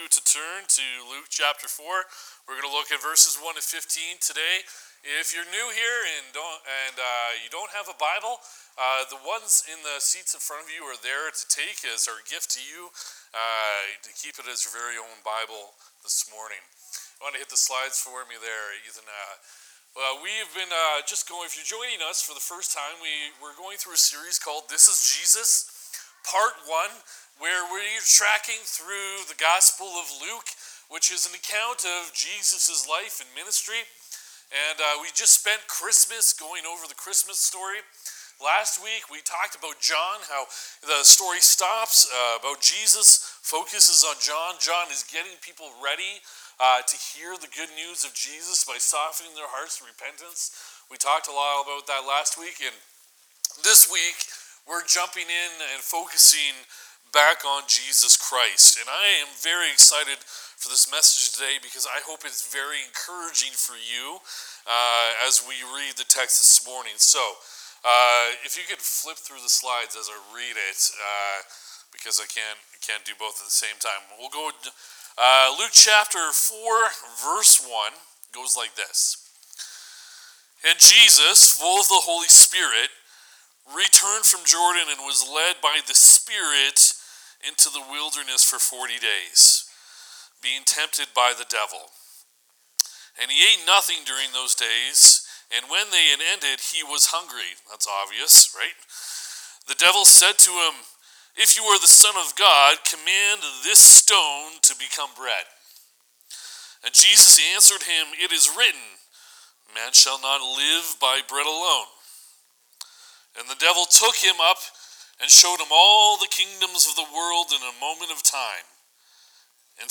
Feb 1 Sermon